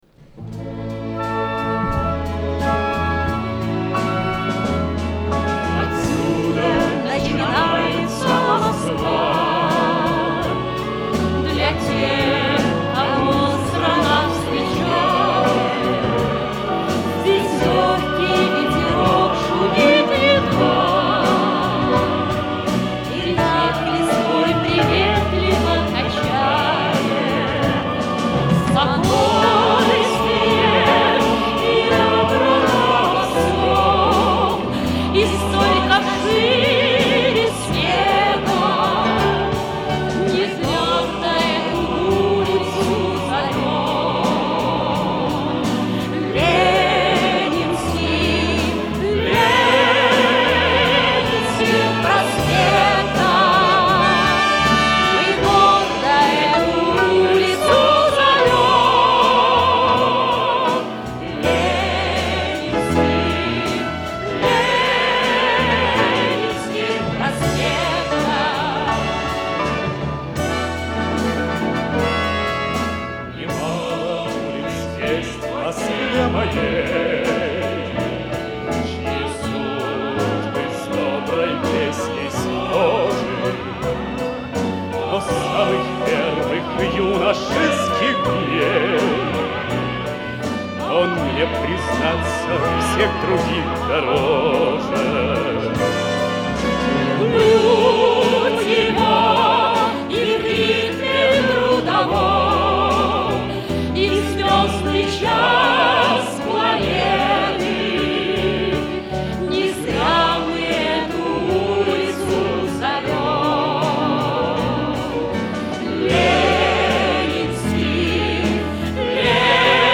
Запись из Колонного зала Дома союзов 23 ноября 1981 года.